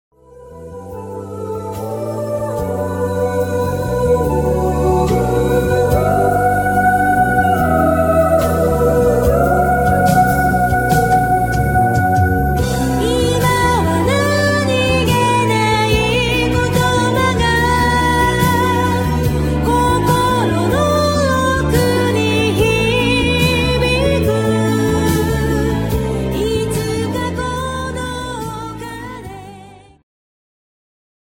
Segment Progressive
Rock
Guitar Future
Synthesizer Future
Symphonic
Vocal Future